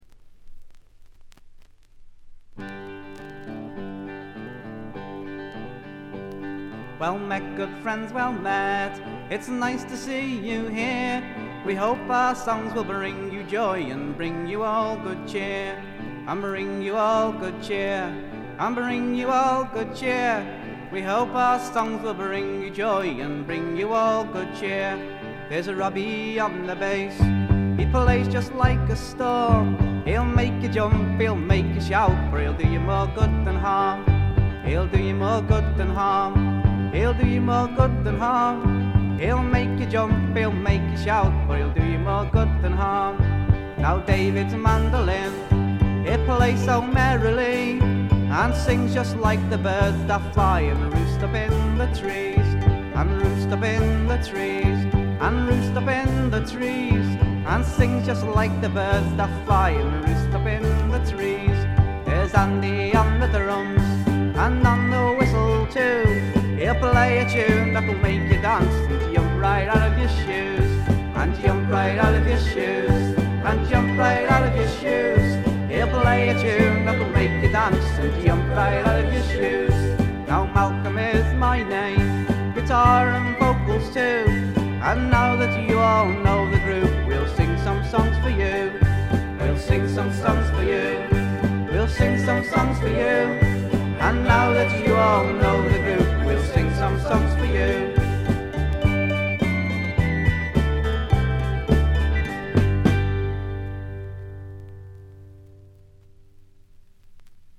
見た目に反してプレスがいまいちのようで、ところどころでチリプチ。プツ音少々。
リヴァプールの男性4人組フォークバンドによるメジャー級の素晴らしい完成度を誇る傑作です。
格調高いフォークロックの名盤。
試聴曲は現品からの取り込み音源です。
Recorded At - Canon Sound Studio, Chester